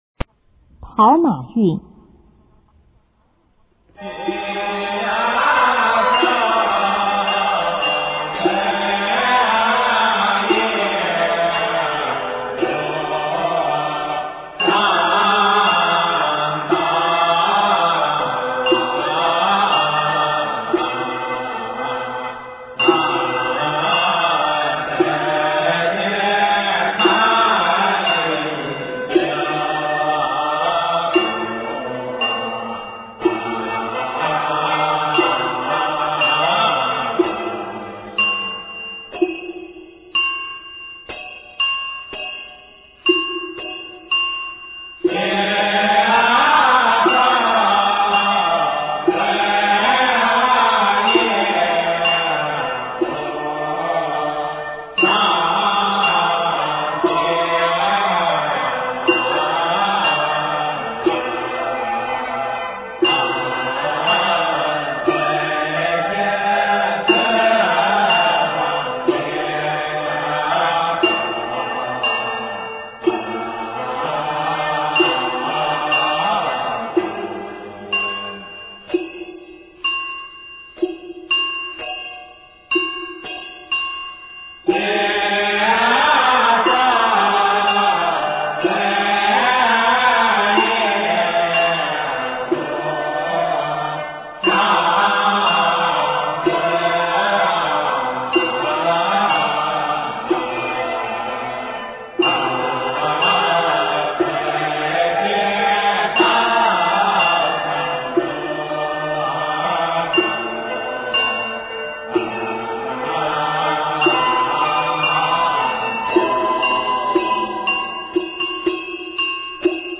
中国道教音乐 全真正韵 跑马韵（同三柱香韵）